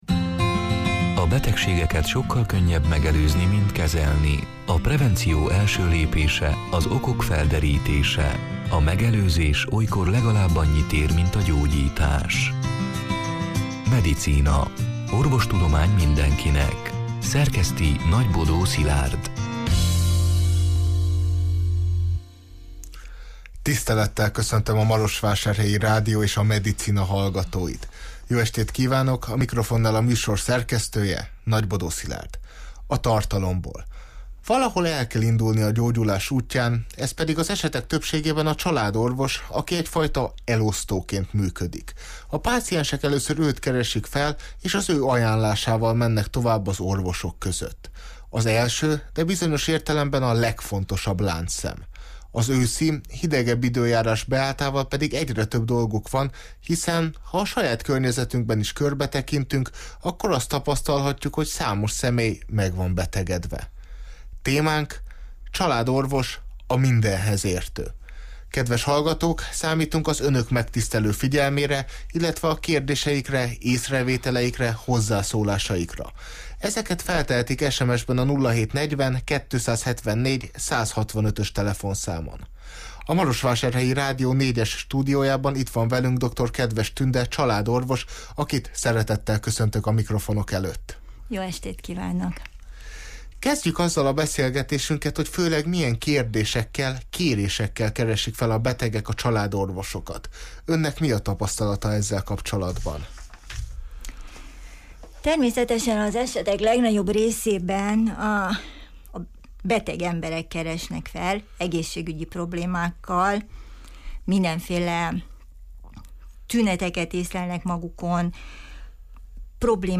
A Marosvásárhelyi Rádió Medicina (elhangzott: 2022. szeptember 28-án, szerdán este nyolc órától élőben) c. műsorának hanganyaga: Valahol el kell indulni a gyógyulás útján, ez pedig az esetek többségében a családorvos, aki egyfajta elosztóként működik. A páciensek először őt keresik fel és az ő ajánlásával mennek tovább az orvosok között.